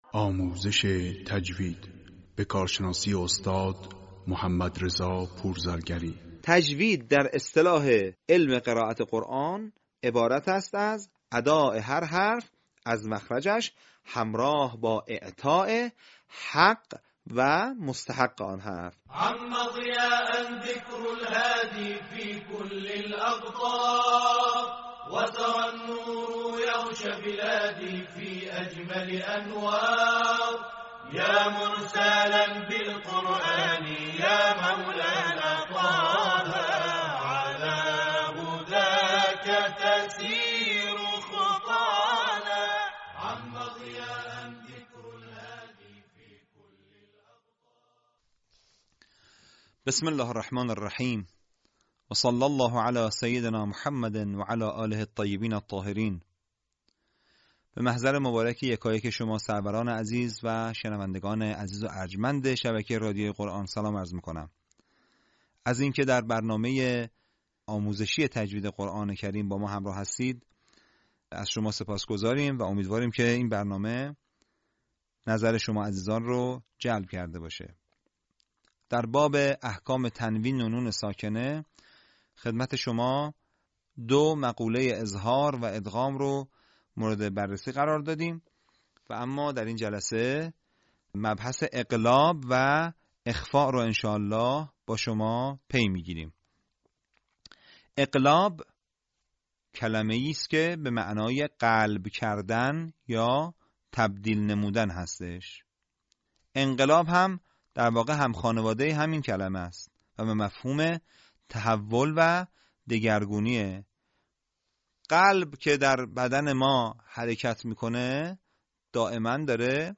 به همین منظور مجموعه آموزشی شنیداری(صوتی) قرآنی را گردآوری و برای علاقه‌مندان بازنشر می‌کند.